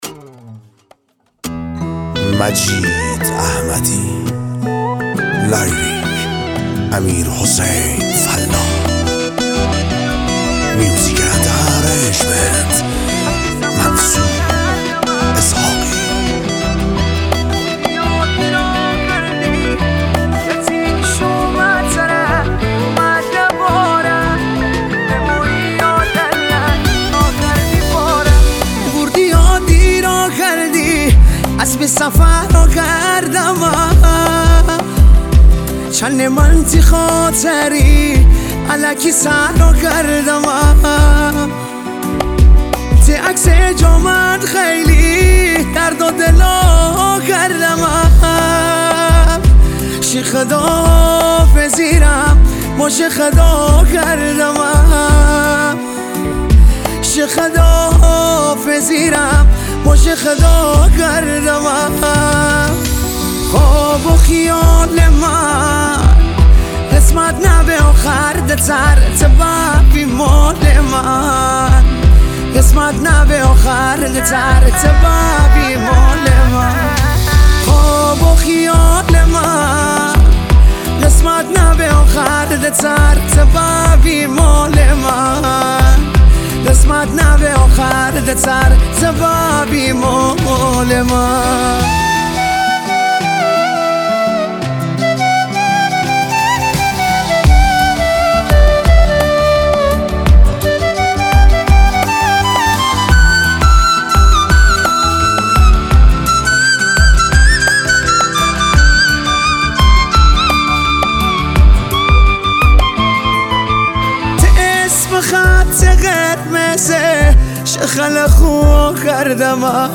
غمگین
با سبک غمگین مازندرانی